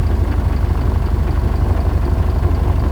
engine_idle1.wav